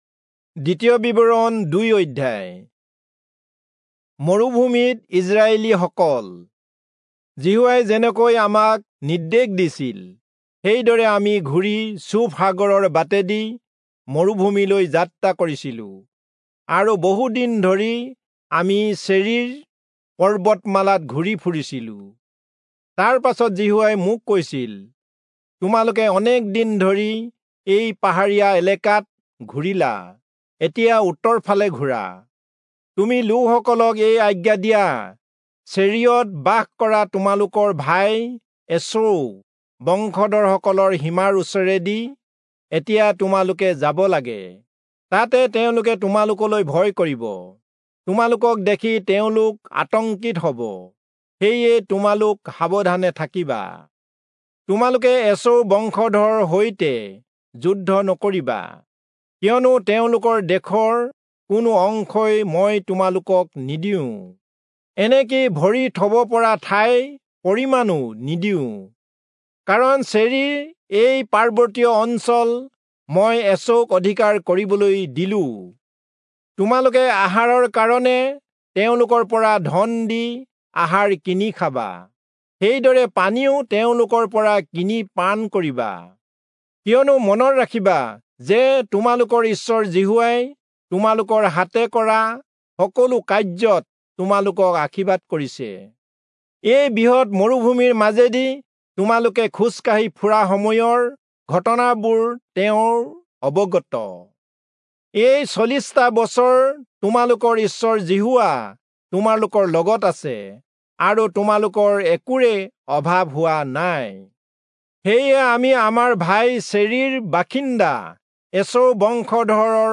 Assamese Audio Bible - Deuteronomy 20 in Ocvkn bible version